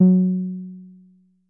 BASS1 F#3.wav